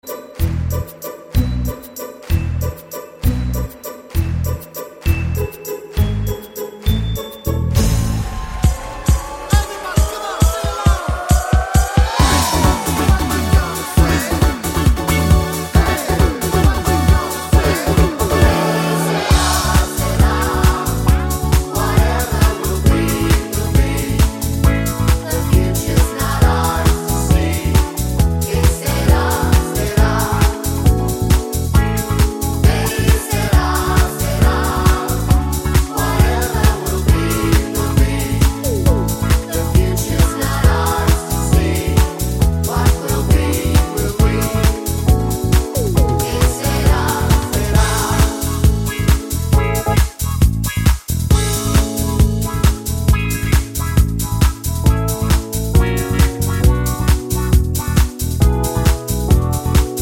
No Sung Backing Vocals Pop (1990s) 3:50 Buy £1.50